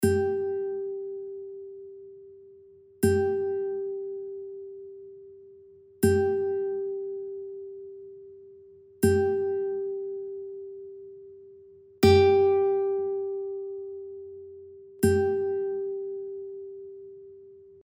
Cтрій До (C),
1-ша струна соль, G (mp3):
Bandurka_C-strij_1_G4.mp3